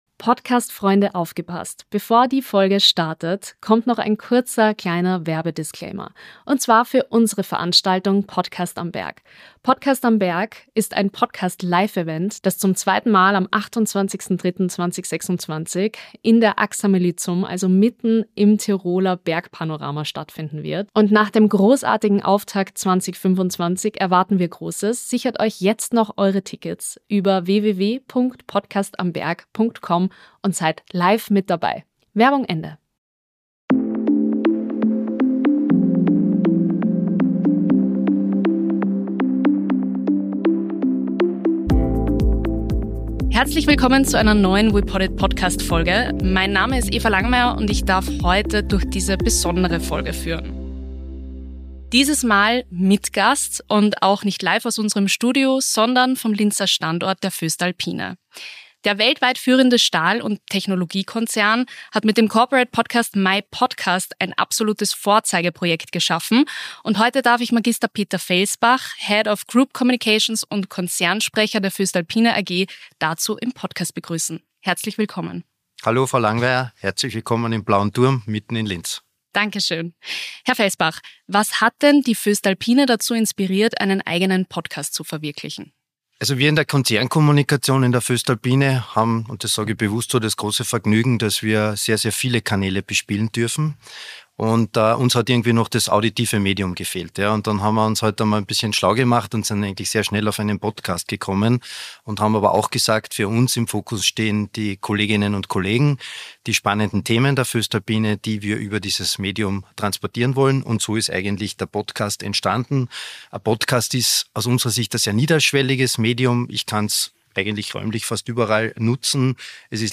einer Interview-Reihe näher beleuchten.